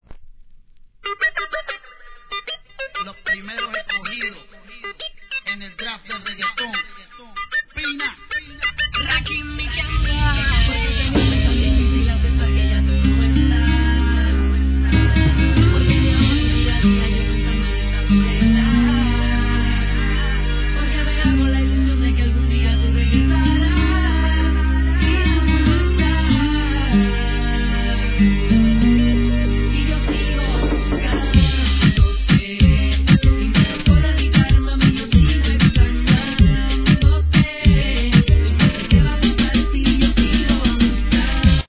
■REGGAETON